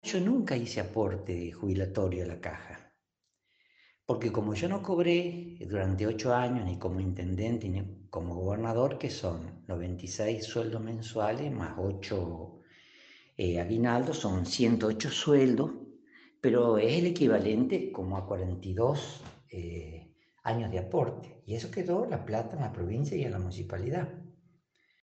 Mario Moine, ex gobernador de la provincia, se comunicó con este Portal para hacer un descargo luego de la publicación de este martes que hacía referencia a declaraciones suyas.